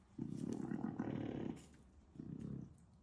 Grey Crowned Crane
Contact Call | A soft, purring call expressing reassurance and location.
Grey-Crowned-Crane-Contact.mp3